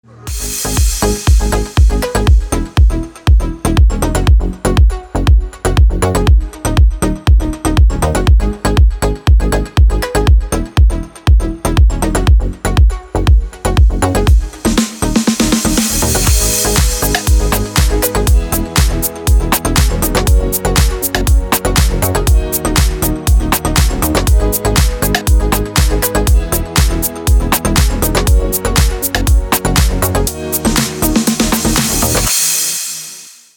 Фрагменты из Deep House жанра
• Песня: Рингтон, нарезка